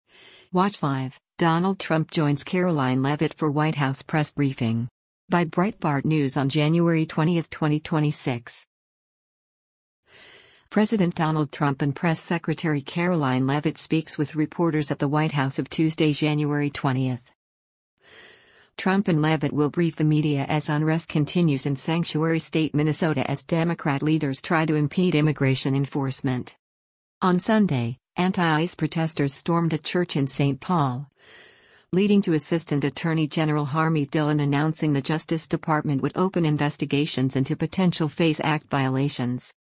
President Donald Trump and Press Secretary Karoline Leavitt speaks with reporters at the White House of Tuesday, January 20.